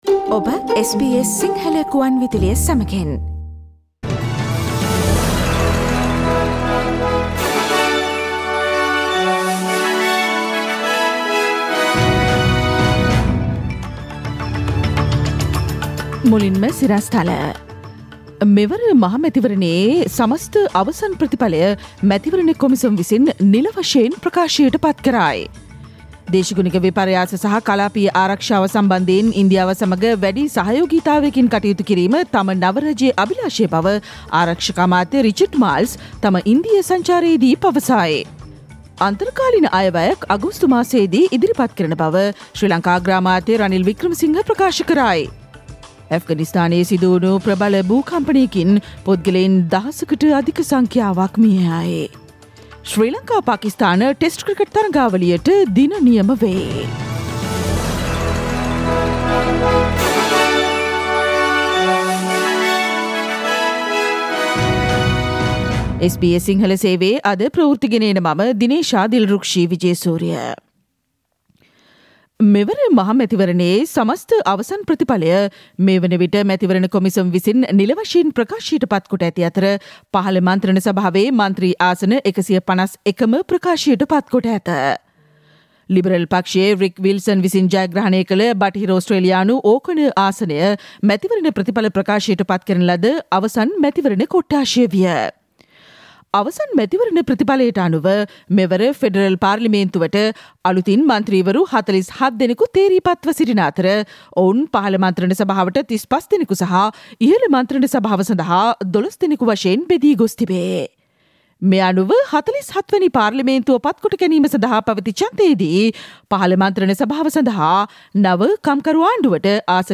ඉහත චායාරූපය මත ඇති speaker සලකුණ මත ක්ලික් කොට ජූනි 23දා, බ්‍රහස්පතින්දා SBS සිංහල ගුවන්විදුලි වැඩසටහනේ ප්‍රවෘත්ති ප්‍රකාශයට ඔබට සවන්දිය හැකියි.